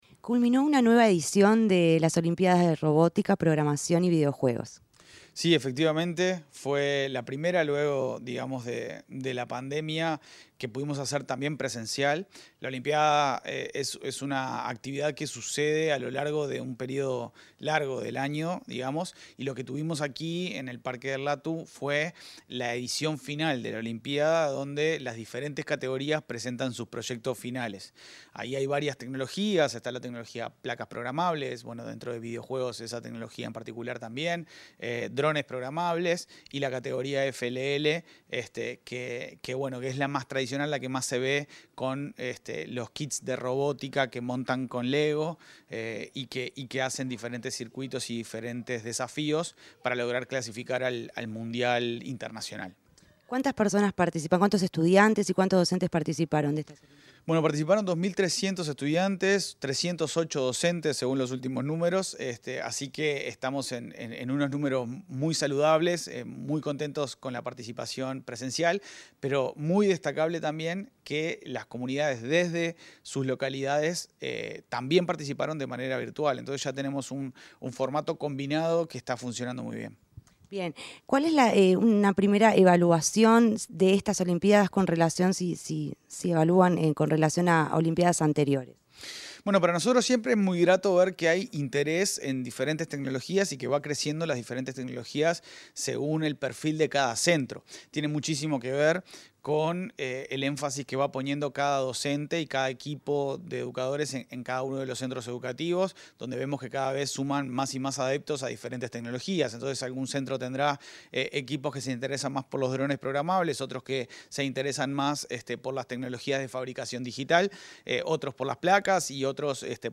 Entrevista al presidente del Ceibal, Leandro Folgar | Presidencia Uruguay